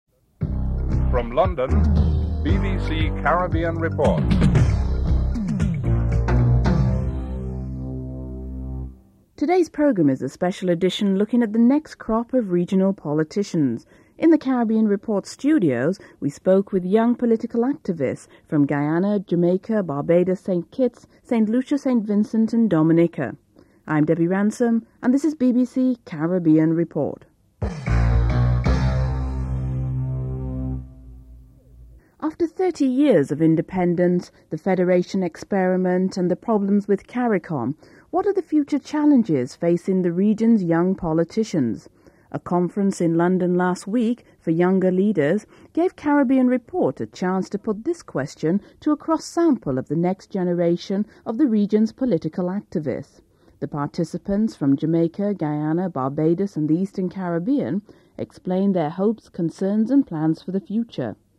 1. Headlines (00:00-00:28)
Interviews